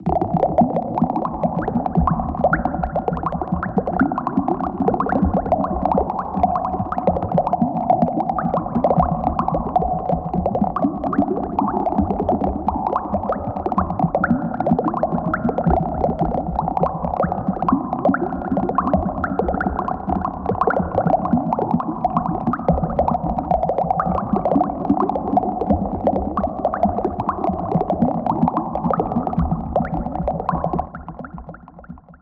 hab spaßeshalber mal deine waves durch meine effekte gejagt:
waves2-dubelements.mp3